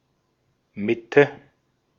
Ääntäminen
US : IPA : [ˈmɪd.l̩]